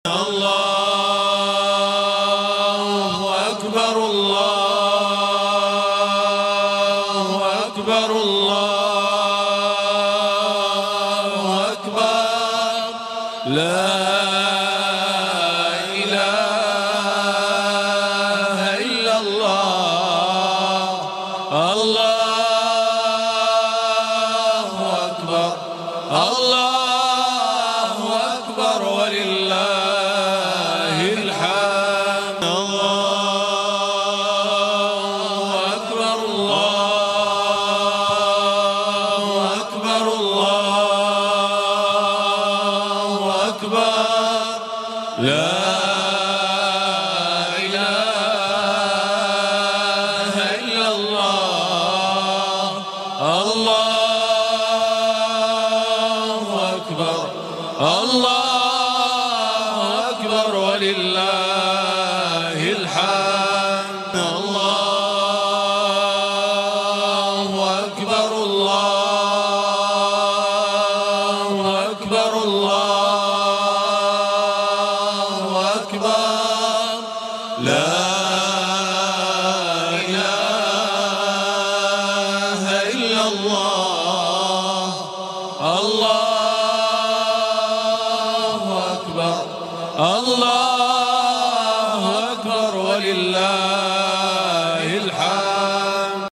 تكبيرات العيد
takbeerat-mp3.mp3